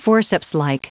Prononciation du mot forcepslike en anglais (fichier audio)
Prononciation du mot : forcepslike